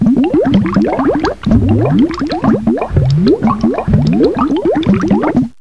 Bubbling